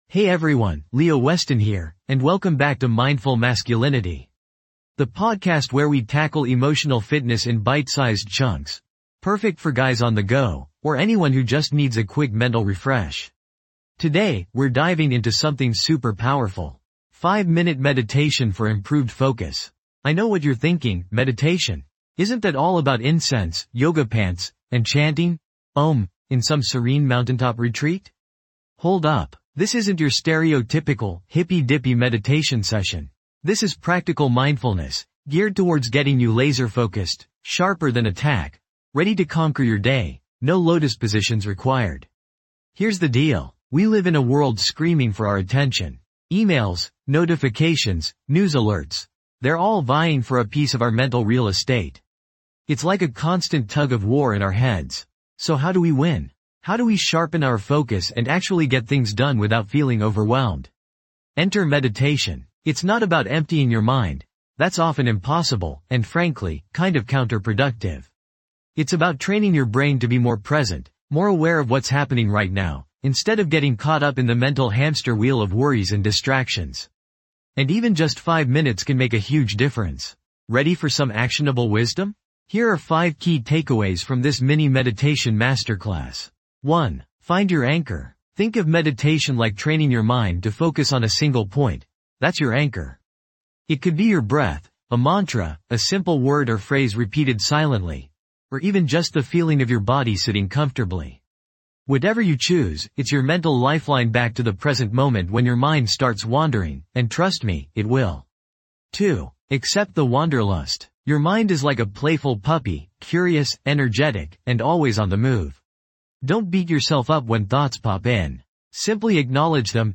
Dive deep into a 5-minute guided meditation designed to enhance your focus and mental clarity.
This podcast is created with the help of advanced AI to deliver thoughtful affirmations and positive messages just for you.